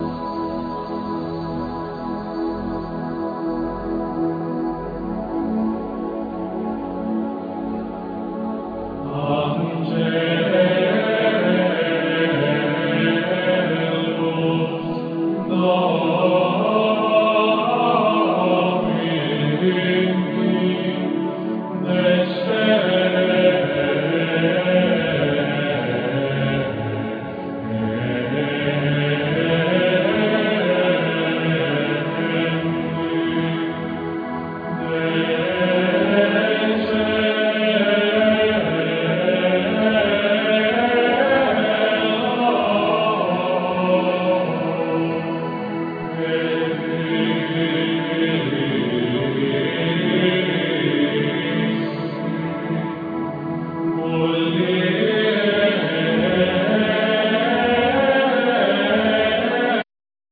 Vocals
Keyboards